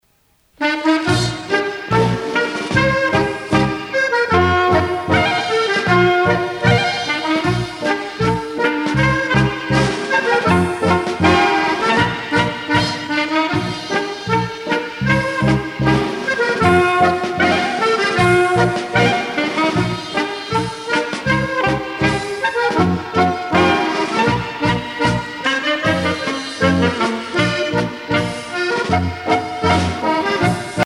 Orchestre de variétés